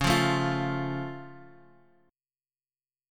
C#+ chord {x 4 3 2 x 1} chord
Csharp-Augmented-Csharp-x,4,3,2,x,1.m4a